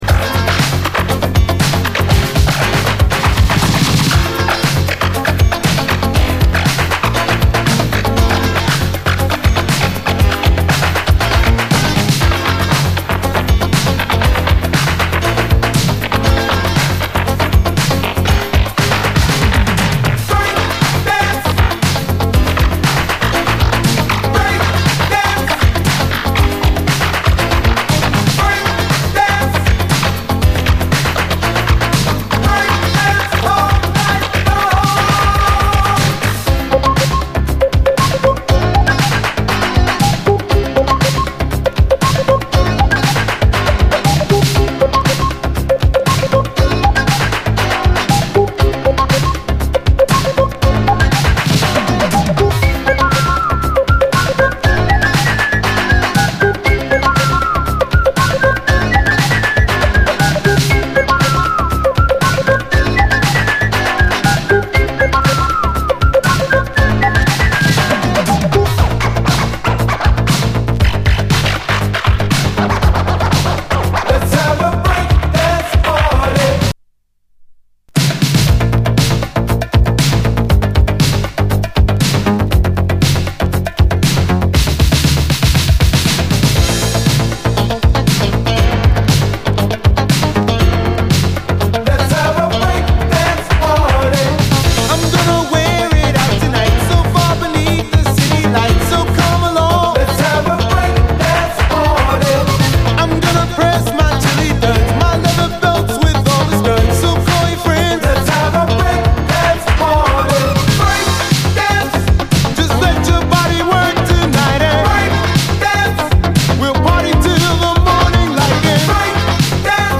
スリリングかつゴージャスかつアダルトなムード
このビシバシ鳴るハイハットの刻みがタマンナイです。